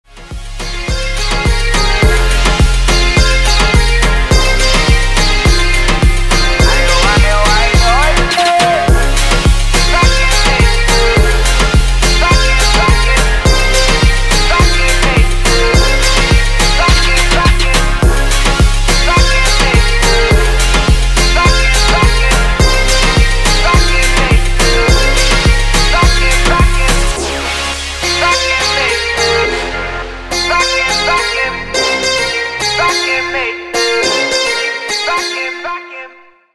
• Качество: 320, Stereo
мужской голос
восточные мотивы
dance
EDM
Electronica
Громкий рингтон с восточным звучанием индийского колорита